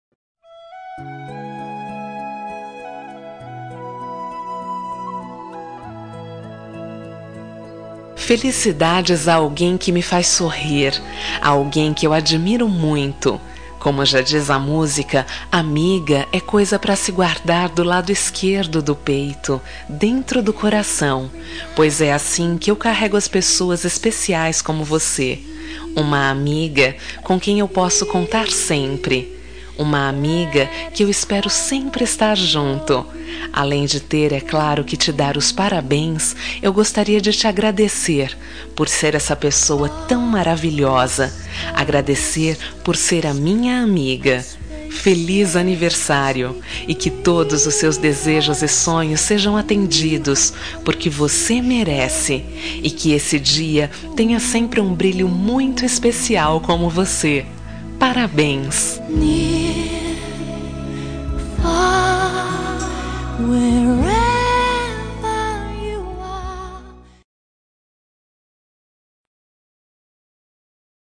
Telemensagem Aniversário de Amiga – Voz Feminina – Cód: 1519